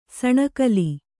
♪ saṇakli